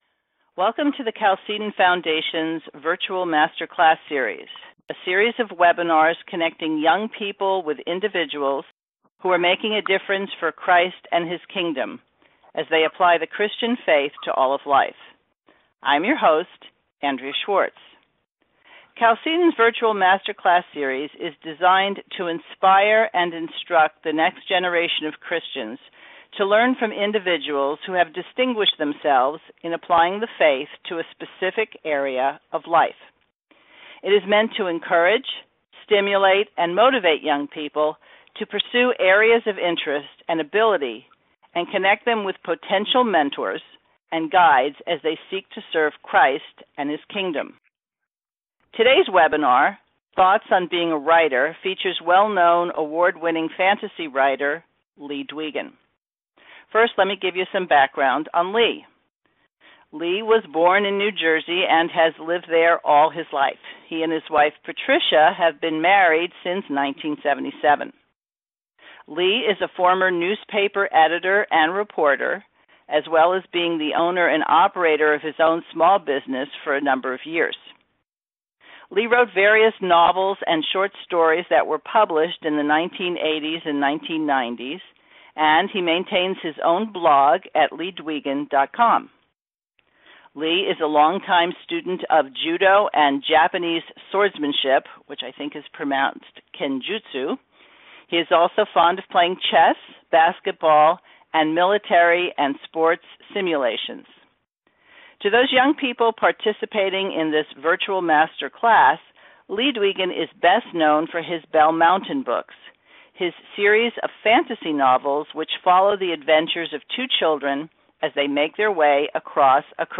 Thoughts On Being a Writer ~ Webinar Discussion